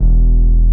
16BASS01  -L.wav